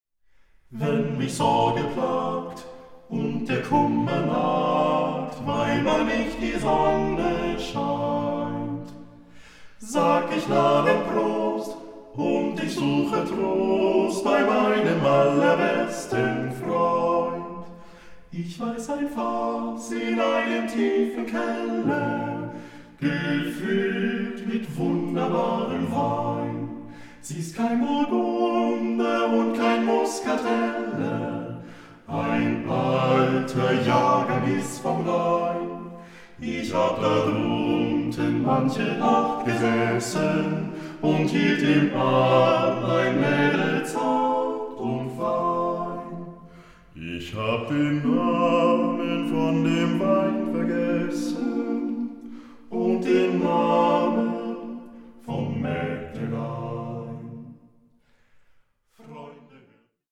award-winning vocal ensemble
the eight singers revive an entire folk culture!